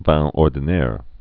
(văɴ ôr-dē-nâr)